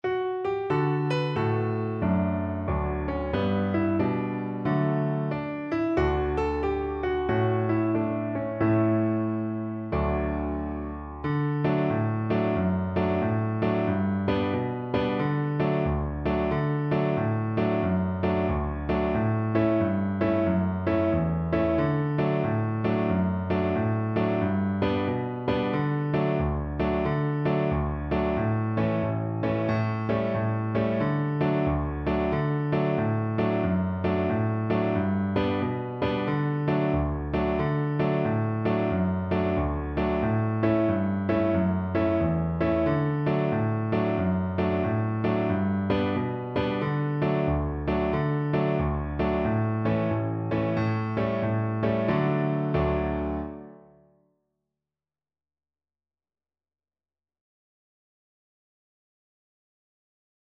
Cello version
With a swing =132
Traditional (View more Traditional Cello Music)
Sea Shanties for Cello